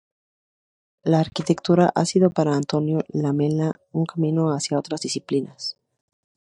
ar‧qui‧tec‧tu‧ra
/aɾkiteɡˈtuɾa/